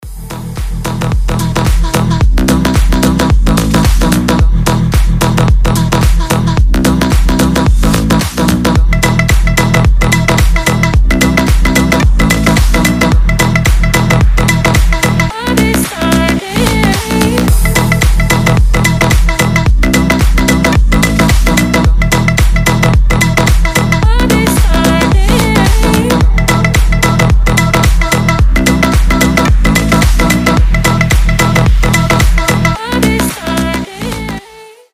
ритмичные
восточные
house
этнические
индийские
Ритмичный и заводной индийский мотив